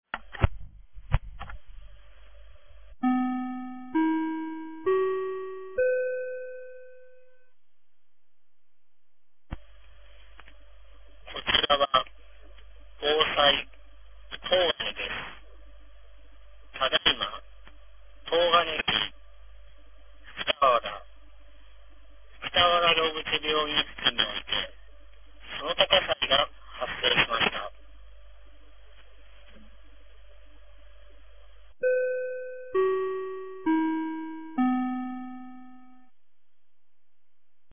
2023年12月04日 10時58分に、東金市より防災行政無線の放送を行いました。